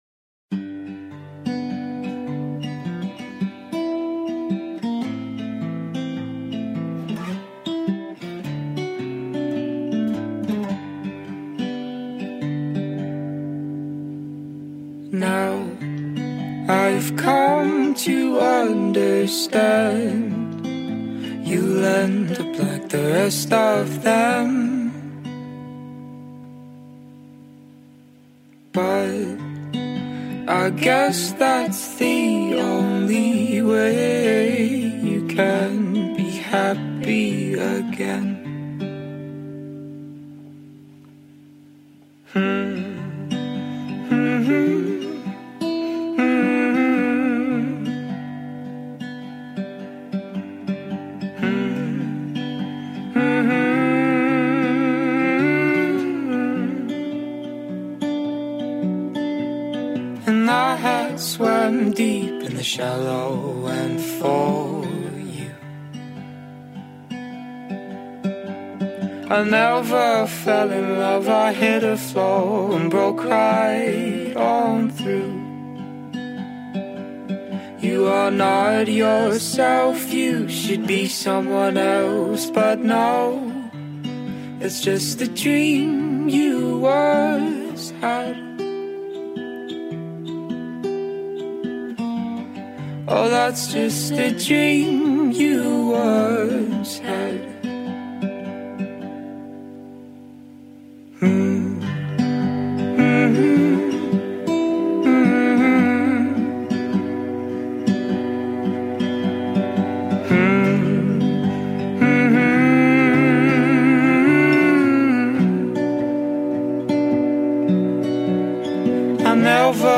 indie-folk flavored acoustic track